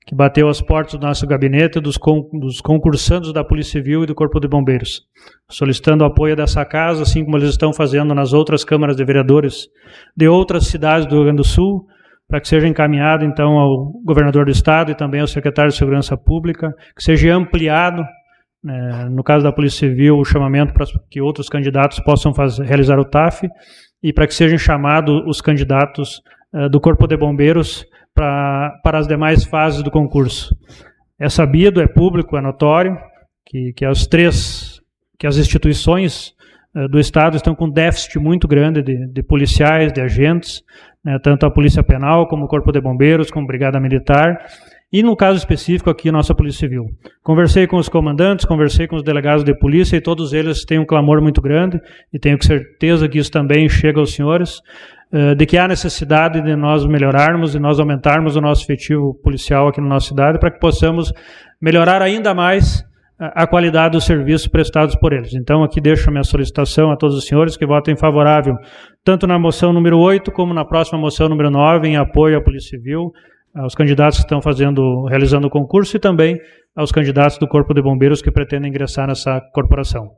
SONORA GILMAR BISCHOFF: